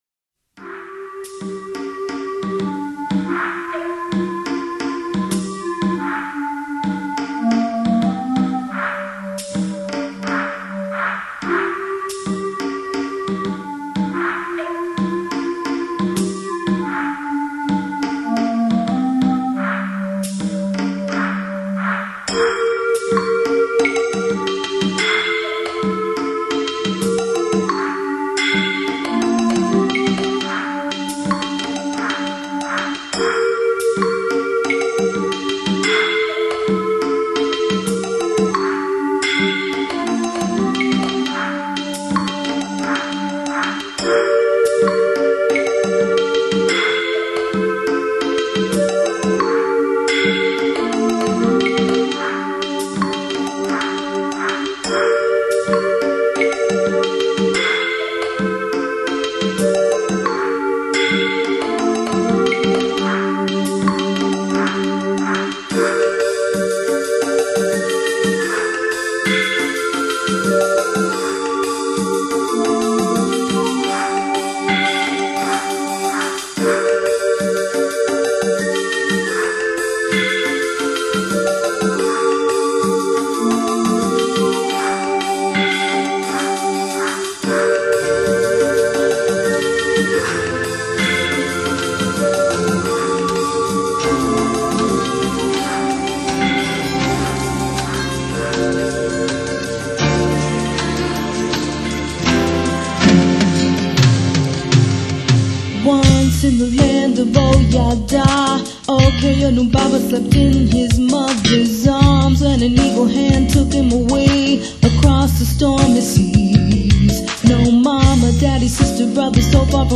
an African melody
inharmonics with a grand, sweeping anthem.
acoustic and electric guitar work